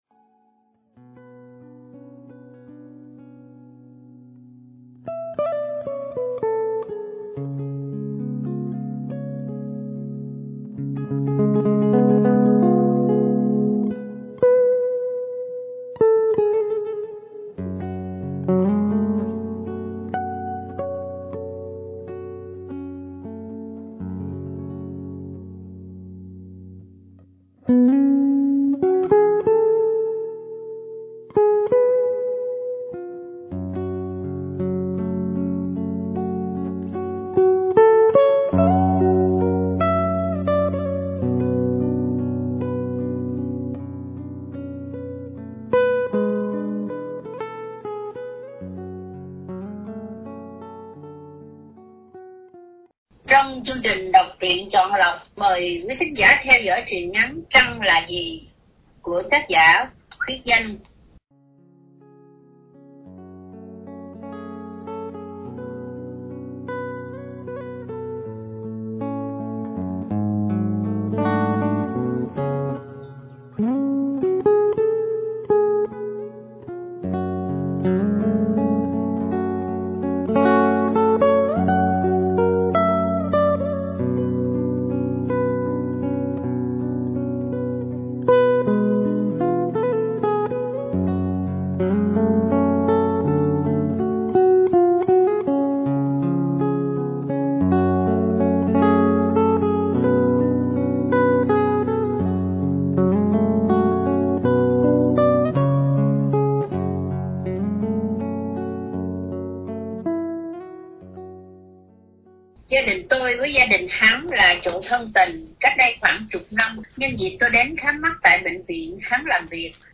Đọc Truyện Chọn Lọc -Truyện Ngắn “Trăng Là Gì ?” – Khuyết Danh – Radio Tiếng Nước Tôi San Diego